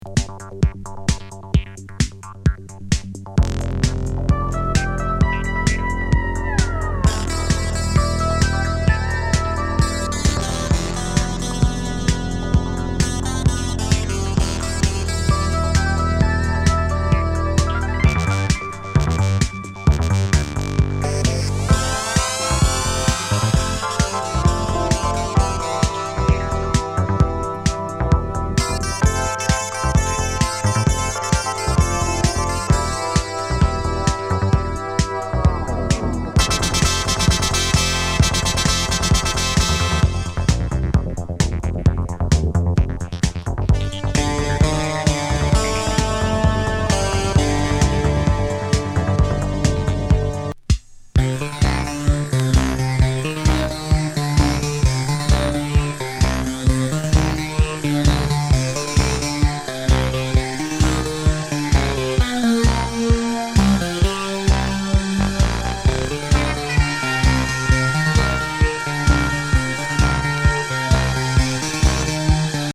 映画主題歌を近未来グッドタイミー・シンセ・メドレー！